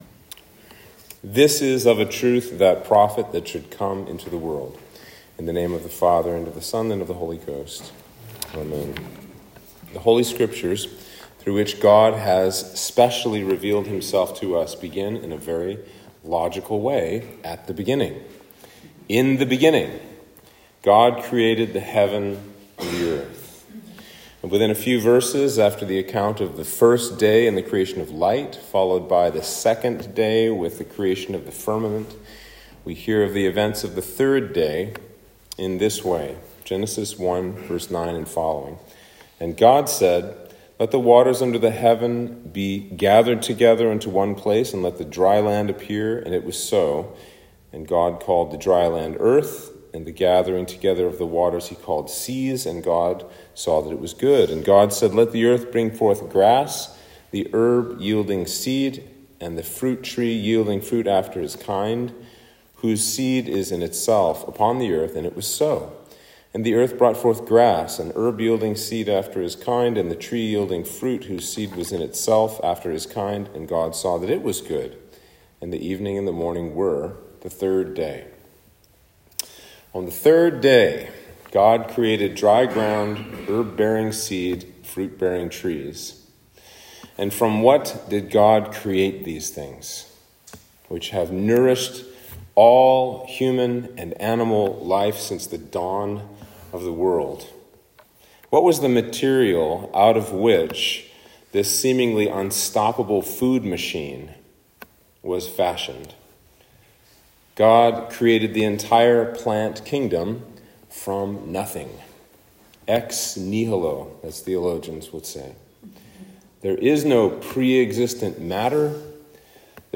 Sermon for Lent 4 - Rose Sunday